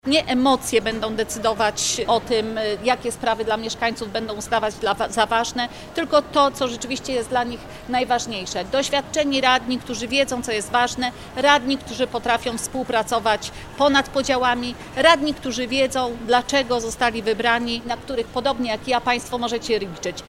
Inicjatywę zrealizował Urząd Miejski Wrocławia i bardzo dobrze – dodaje Mirosława Stachowiak-Różecka, Poseł na Sejm RP, przypominając o wyborach samorządowych.